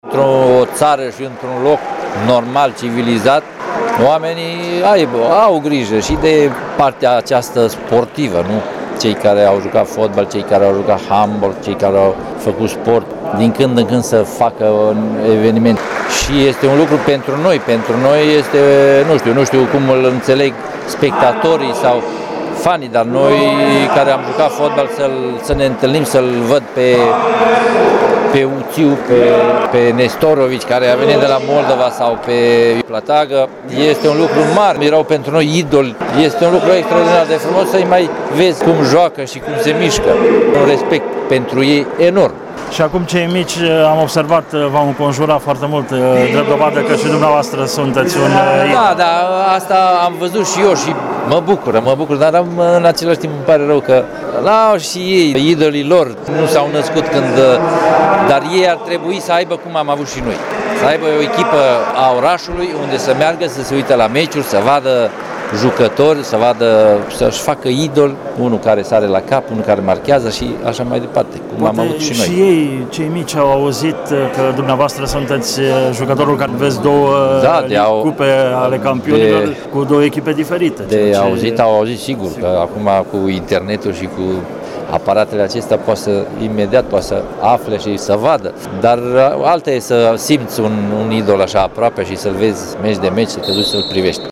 Ediţia a doua a Cupei “Stelele Banatului” la fotbal s-a desfăşurat sâmbătă la Sala Polivalentă din Reşiţa.
Ascultaţi declaraţia lui Miodrag Belodedici: